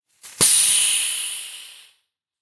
air.ogg